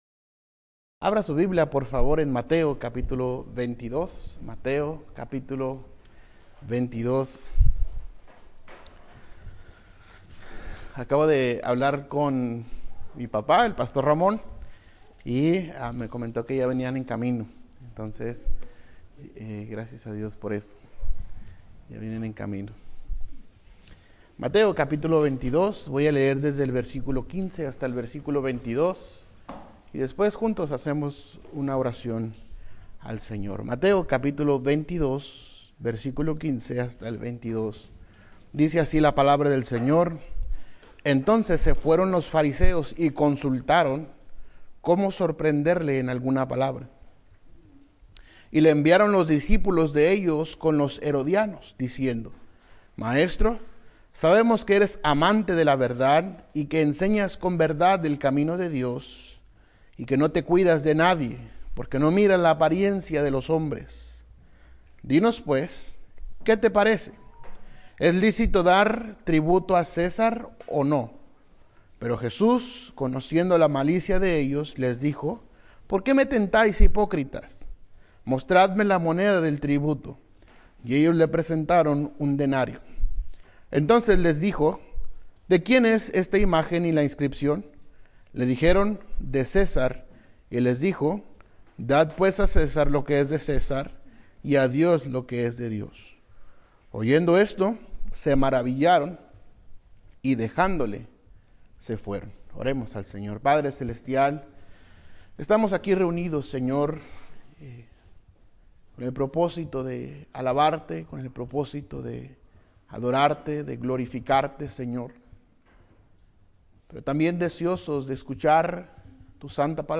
Conferencia Bíblica VII.5 - Erráis, ignorando las Escrituras - Iglesia Cristiana Biblica Monte Moriah